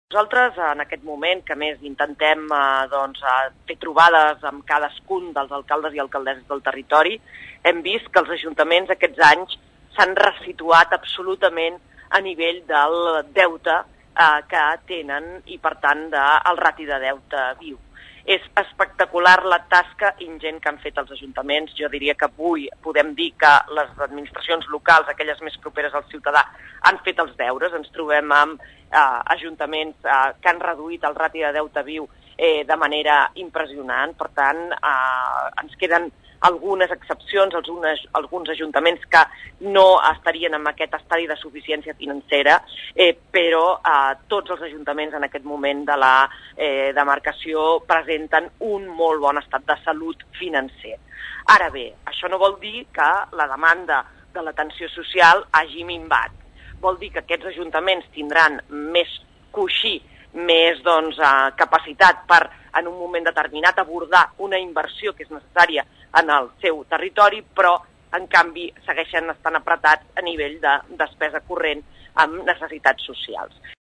La presidenta de la Diputació i alcaldessa de Sant Cugat del Vallès, Mercè Conesa, n’ha parlat a la Xarxa de Comunicació Local.
Mercè Conesa parla de l’esforç dels ajuntaments per reduir el deute.